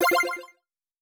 clear_line.wav